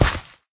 gravel3.ogg